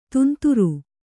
♪ tunturu